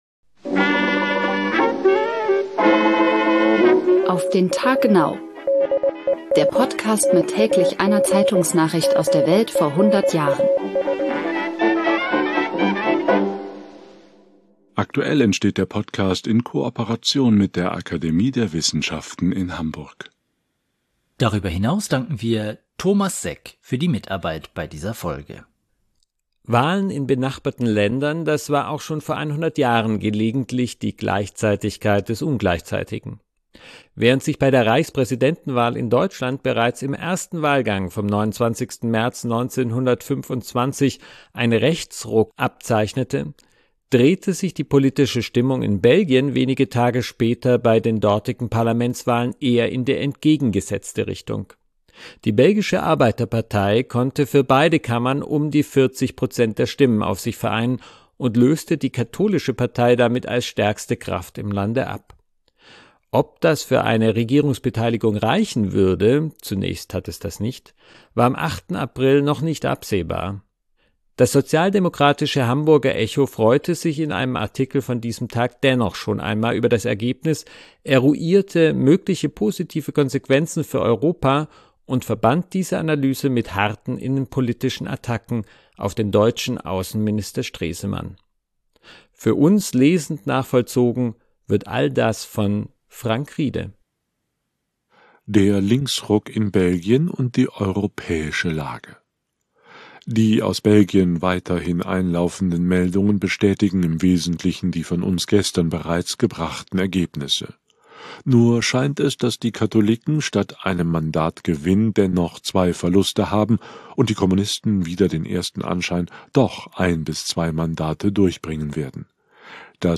Für uns lesend nachvollzogen wird all das